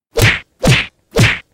3연타+효과음.mp3